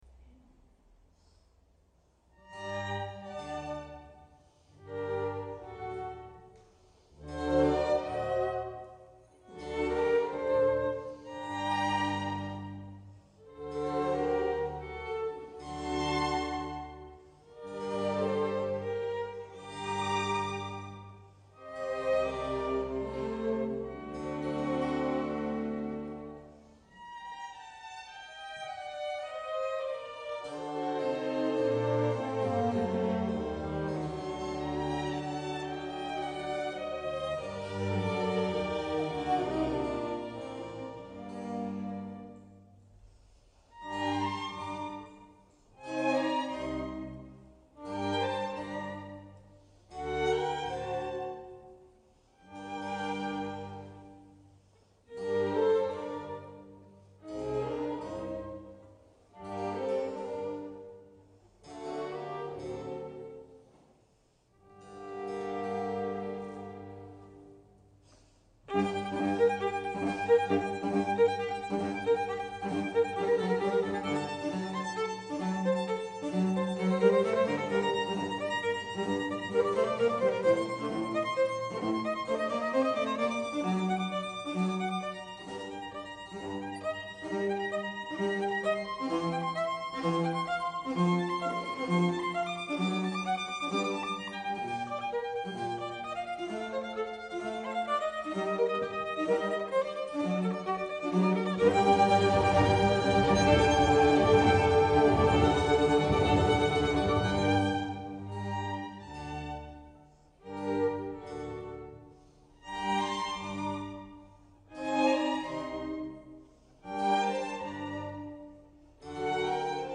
Concerto in sol minore per violino, archi e continuo BV315 "L'Estate"
L'ensemble d'archi della Filarmonica Arturo Toscanini è composto da: